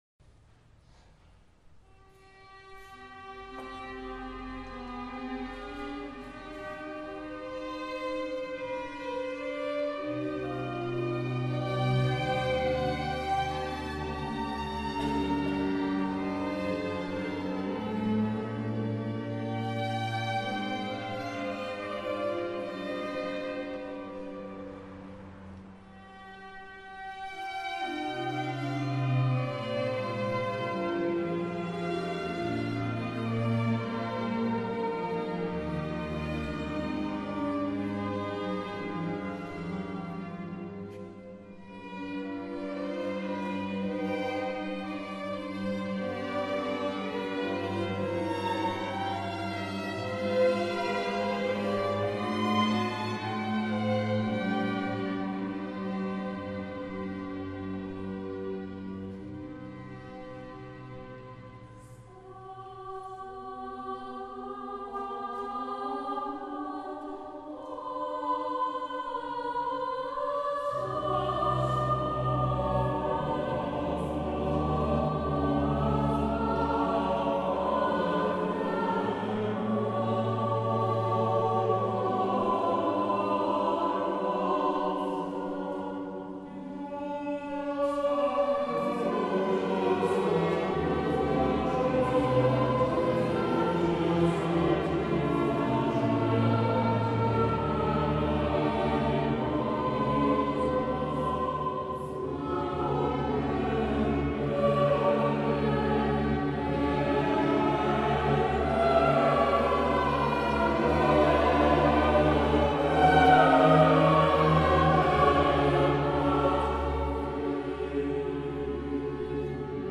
Choir Music